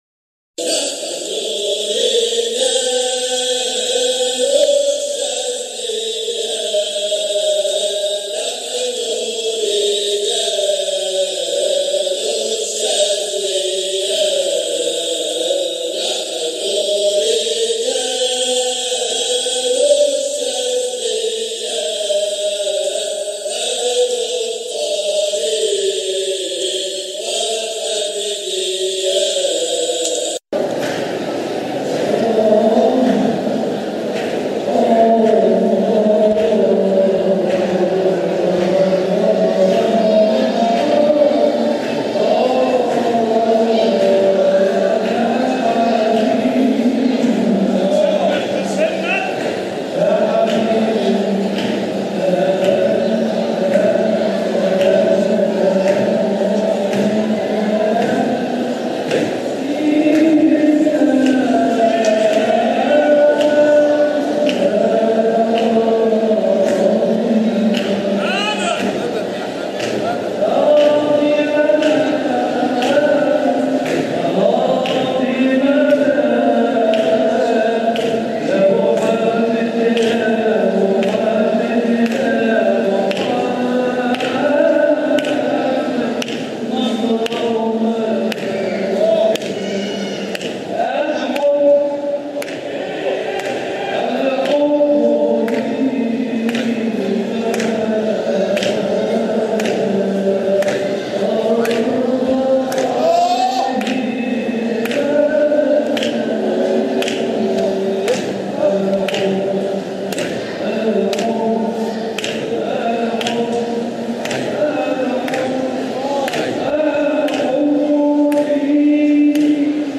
مقاطع من احتفالات ابناء الطريقة الحامدية الشاذلية بمناسباتهم
جزء من حلقة ذكر بمسجد سيدنا احمد البدوى قُدس سره 2017